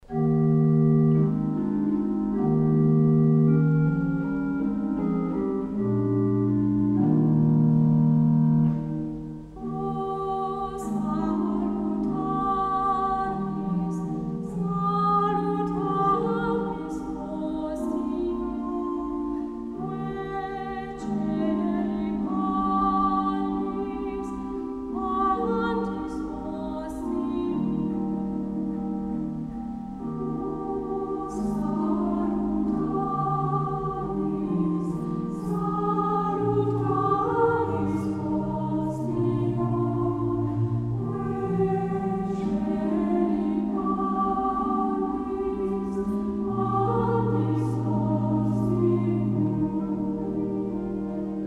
2 et 3 voix égales + orgue
Audios : version d'origine pour voix égales et orgue